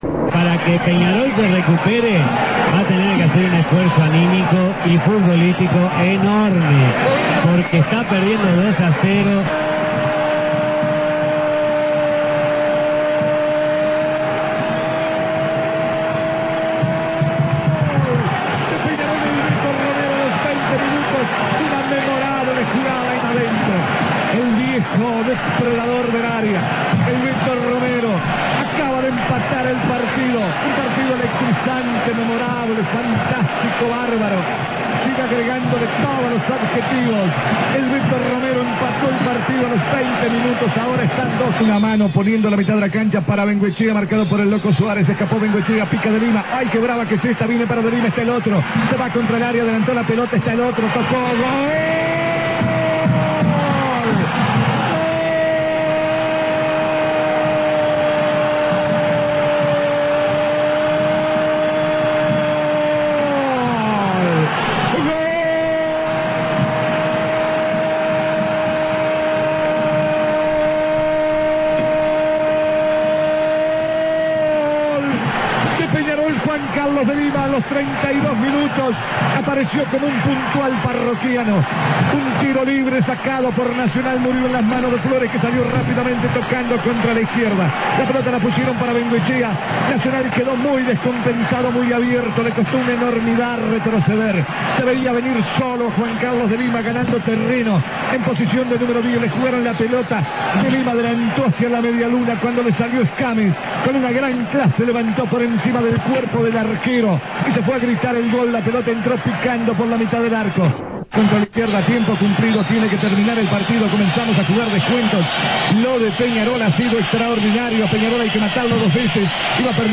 Los más importantes relatos del año del quinquenio